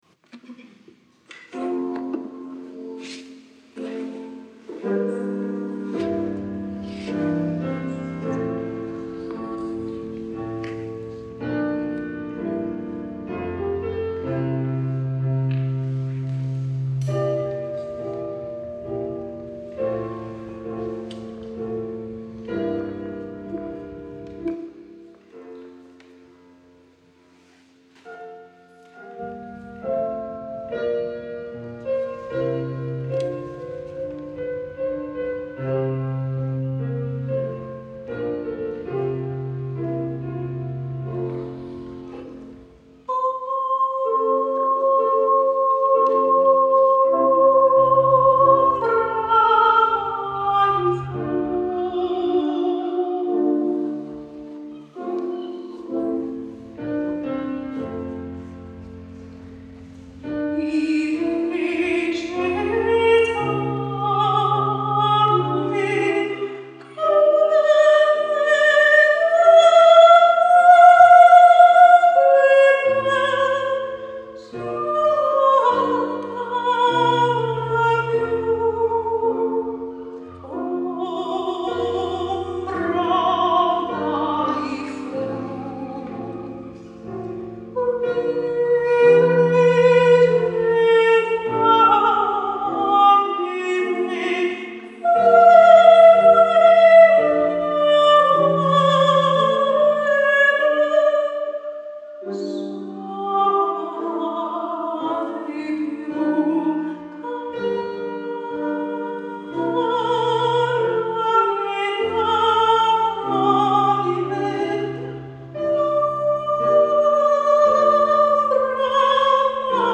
live in concert, selected arias
soprano
piano - live in concert 2024